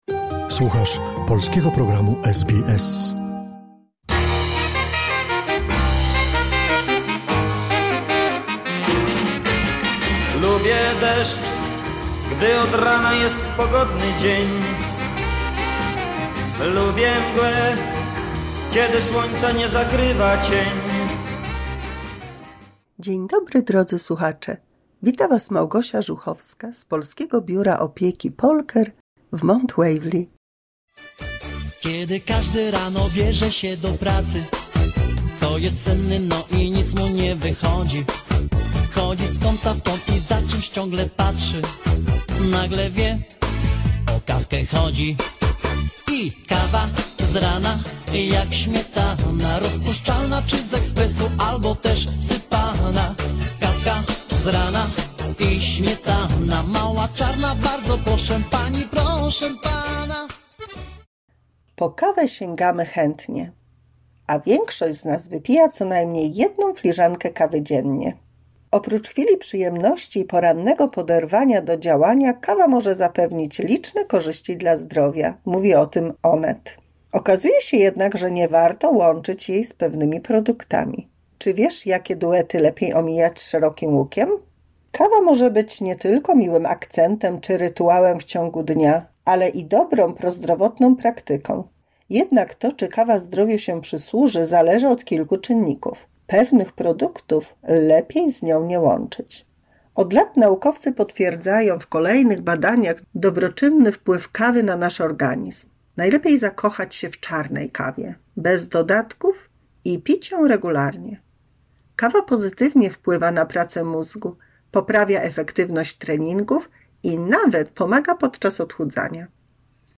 mini słuchowisko dla polskich seniorów